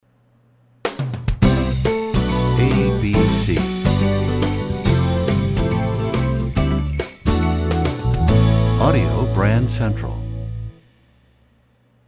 Genre: Instrumental.